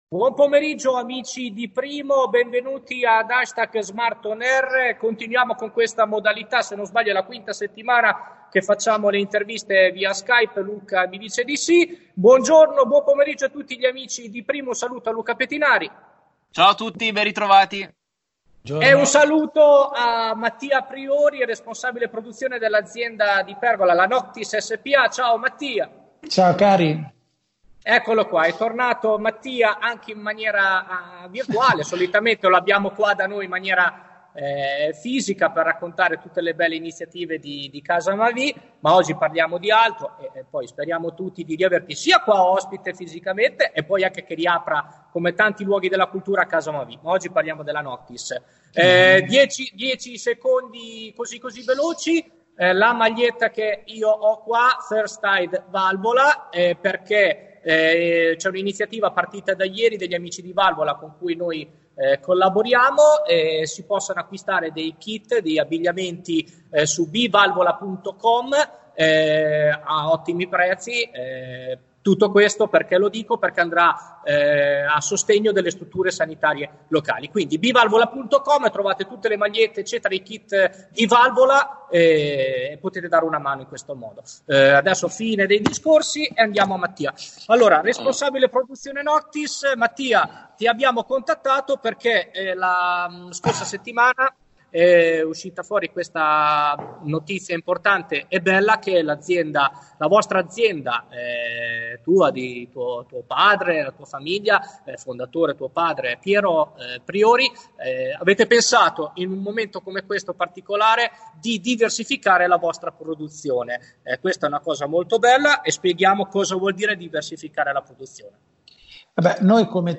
Riconversione per l'emergenza: intervista